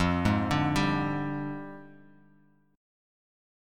Fm6add9 Chord
Listen to Fm6add9 strummed